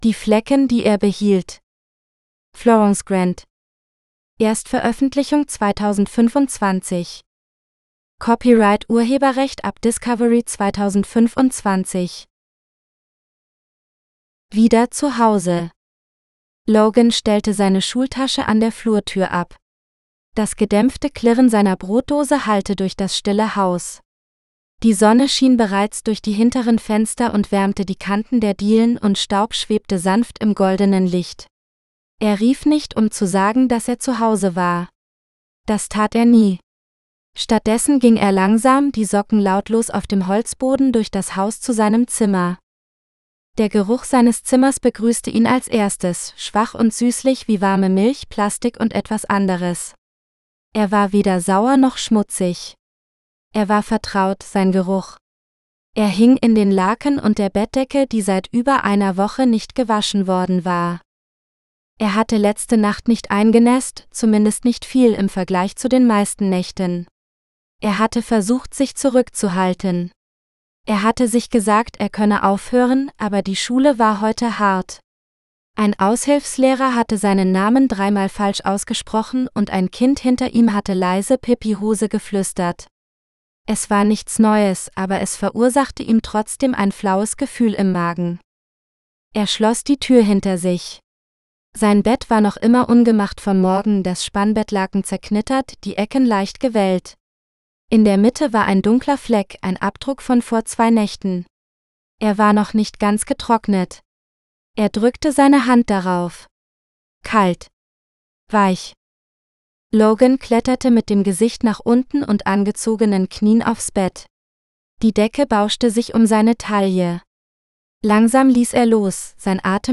The Stains He Kept GERMAN (AUDIOBOOK – female): $US3.99